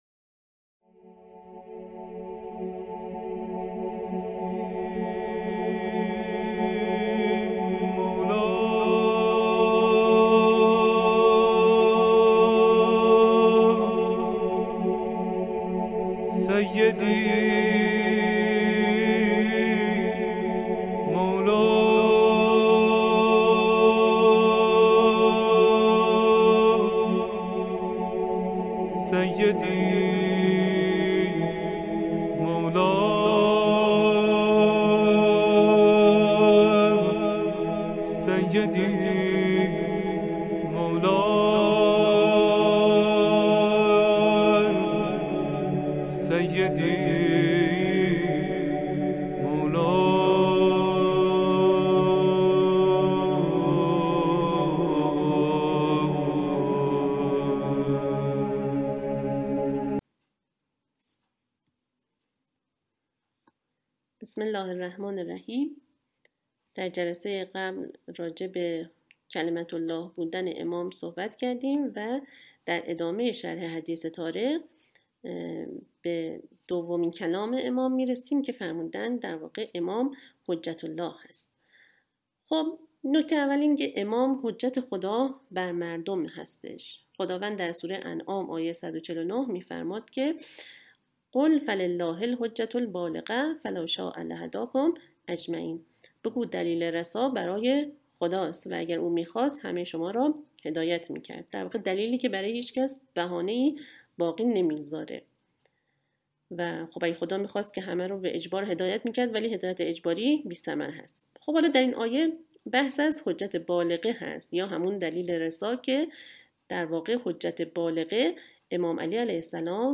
متن سخنرانی معرفت و شناخت امام- شرح حدیث طارق- قسمت ششم- حجه الله-1: